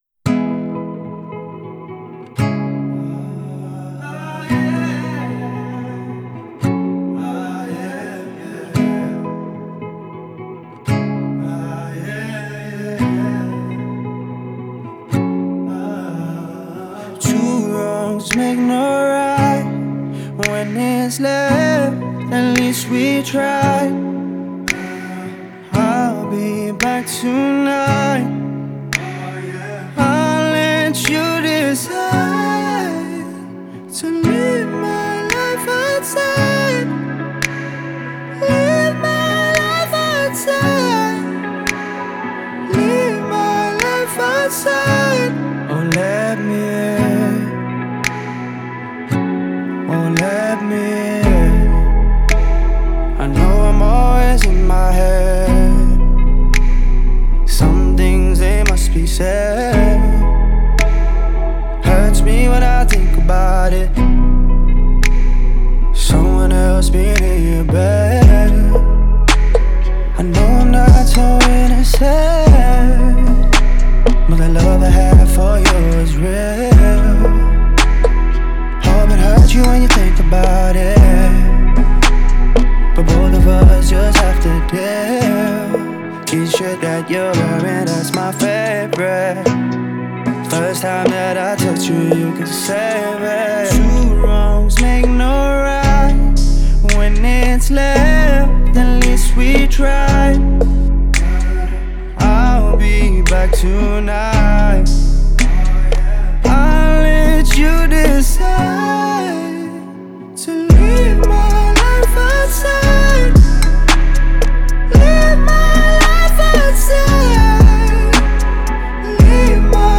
это поп-баллада